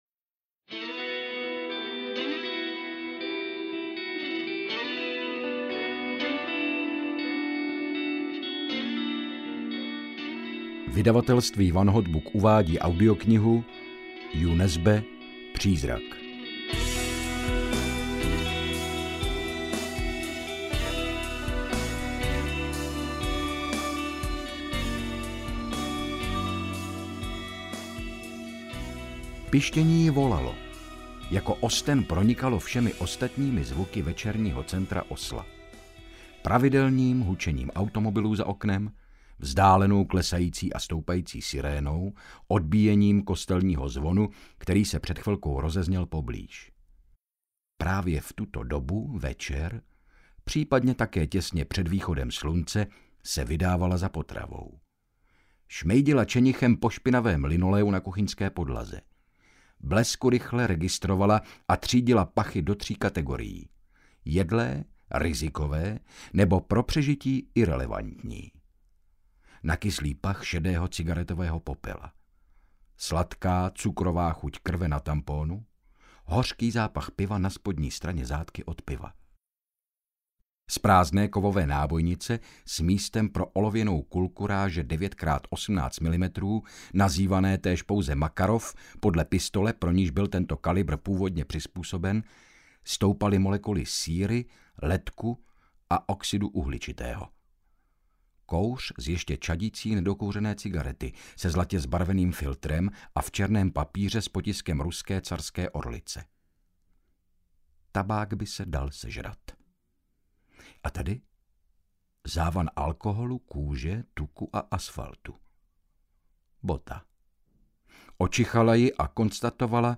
Přízrak audiokniha
Audiokniha Přízrak, kterou napsal Jo Nesbo. Harry Hole se po třech letech strávených v Hong Kongu vrací do Norska, aby se ujal téměř uzavřené vraždy mezi feťáky – údajným pachatelem má totiž být Oleg, potomek jeho femme fatale Ráchel, kterého měl rád jako vlastního syna.
Ukázka z knihy